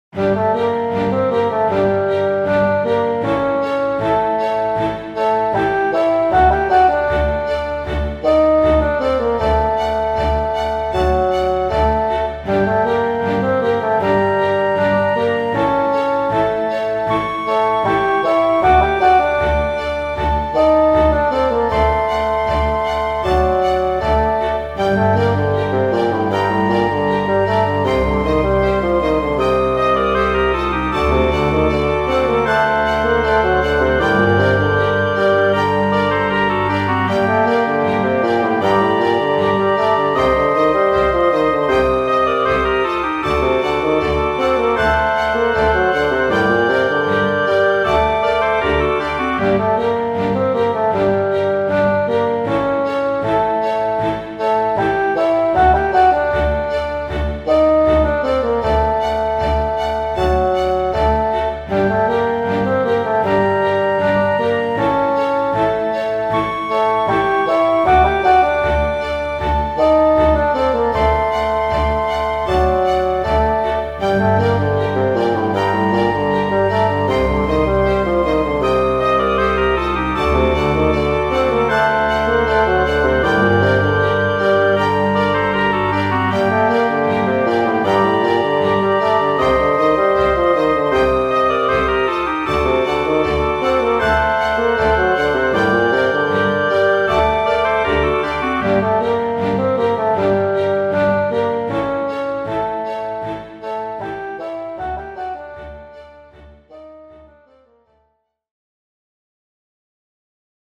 イメージ：洞窟 冒険   カテゴリ：RPG−外・ダンジョン